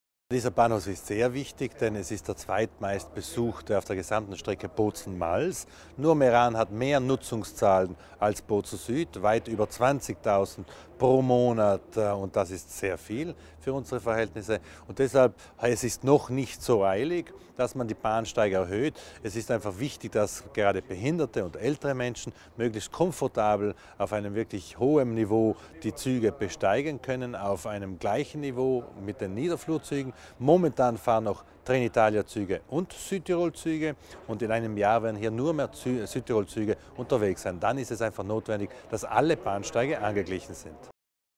Landesrat Widmann erläutert die Neuerungen am Bahnhof Bozen Süd